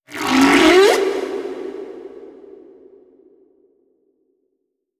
khloCritter_Male16-Verb.wav